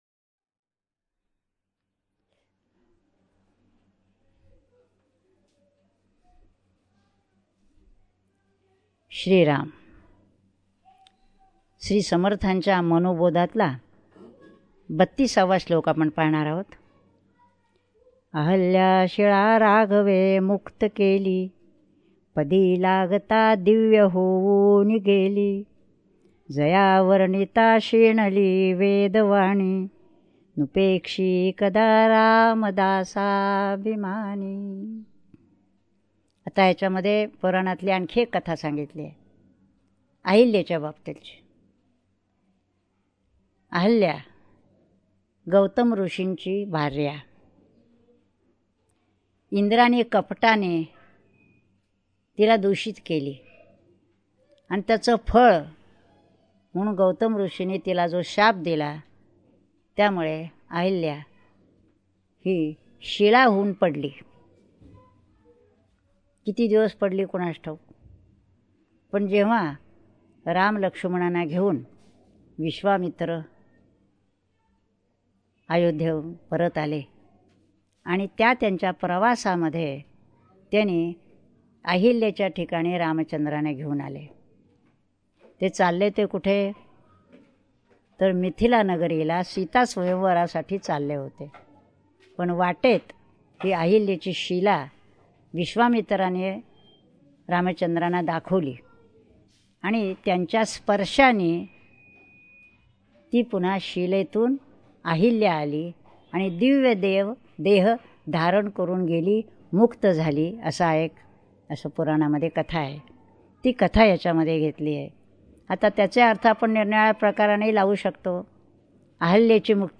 श्री मनाचे श्लोक प्रवचने श्लोक 32 # Shree Manache Shlok Pravachane Shlok 32